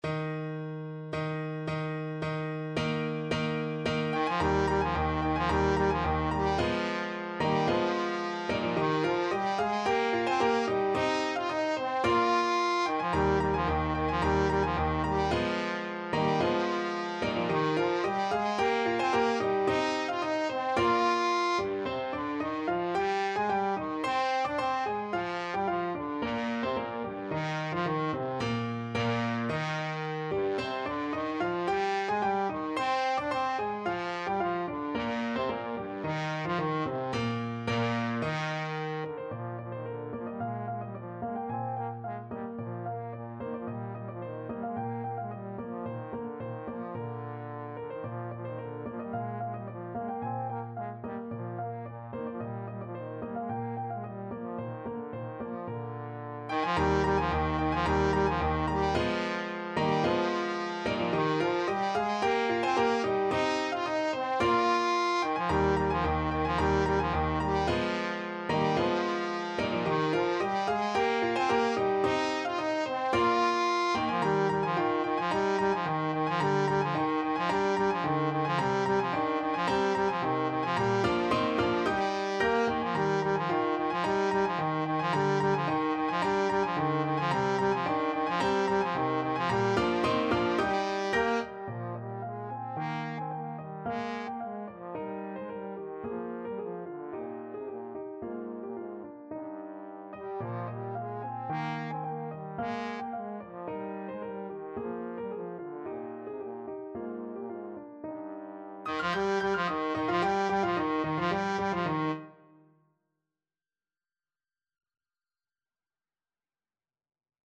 Trombone
Eb major (Sounding Pitch) (View more Eb major Music for Trombone )
= 110 Allegro di molto (View more music marked Allegro)
2/2 (View more 2/2 Music)
G3-F5
Classical (View more Classical Trombone Music)
bergamask_midsummer_night_TBNE.mp3